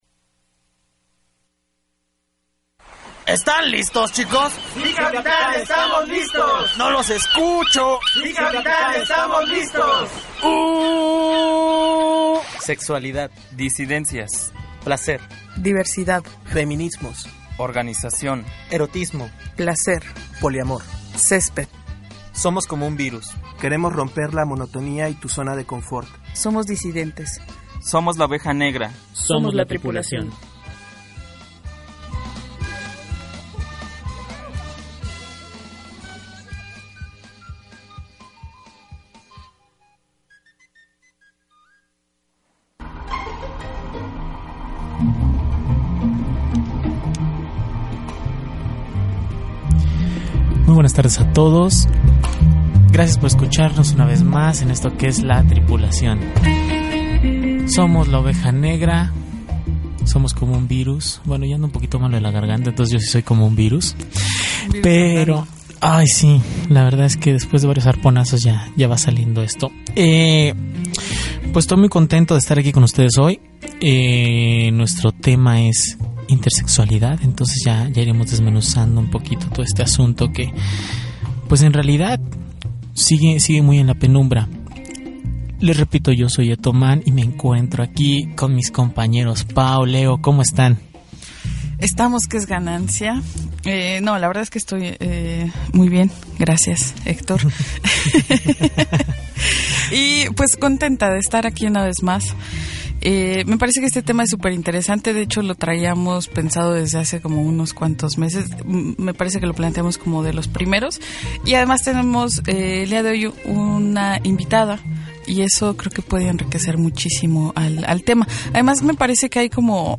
Audio: Programa de Radio sobre Intersexualidad (Participa Brújula Intersexual)